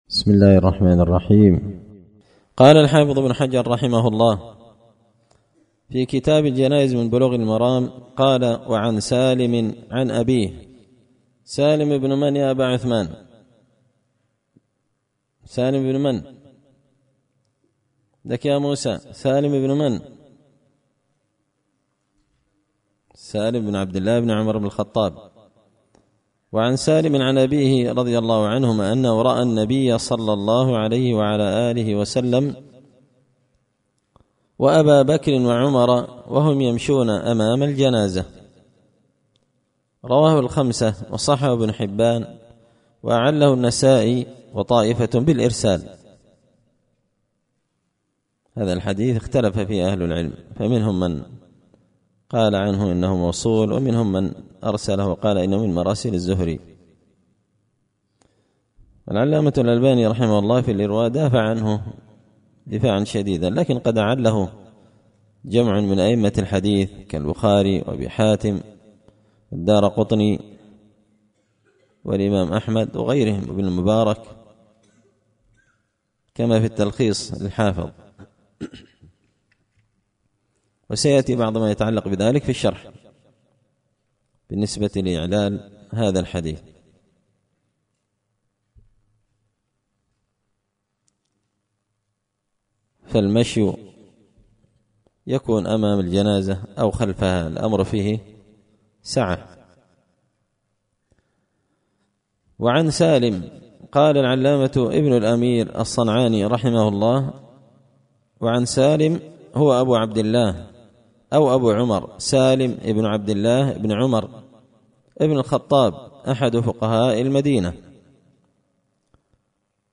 دار الحديث بمسجد الفرقان ـ قشن ـ المهرة ـ اليمن